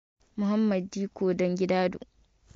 Muhammadu Dikko pronunciation also known as Muhammad Dikko dan Gidado